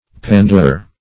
Pandour \Pan"dour\, n.